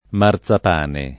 marZap#ne o marzap#ne] s. m. — con -z- sorda la pn. tradizionale, concordante con l’etimo remoto (il top. Martaban) e confermata da antiche var. di forma (massa-, marsa-, marcia-, ecc.), ma ridotta più o meno in minoranza un po’ dappertutto (anche in Tosc.), prob. per effetto della minor frequenza della voce nell’uso (già rilevata dal Petrocchi), con un suo conseguente trattam. simile a quello d’altre voci con marz-, tendenti tutte (salvo marzo, marzio e loro famiglie) verso una pn. sonora o almeno oscillante della -z- — cfr. Marzabotto